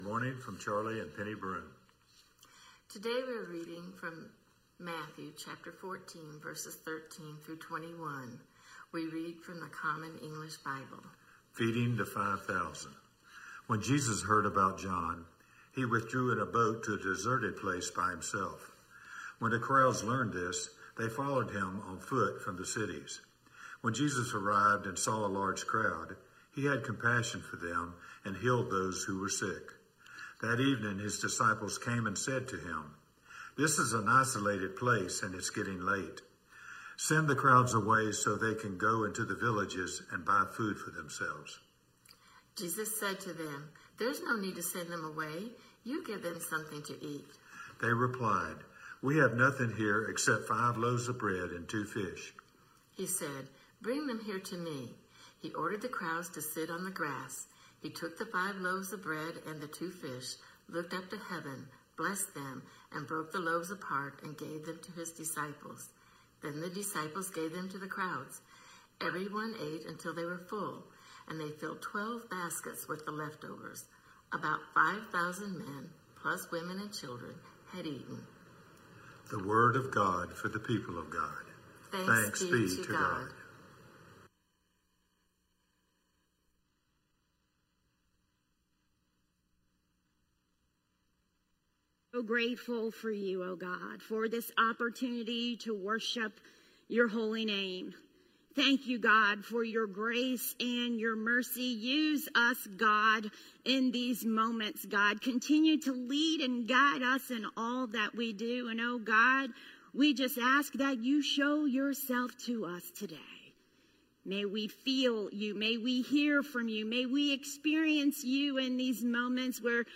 A message from the series "The Gospel of Matthew."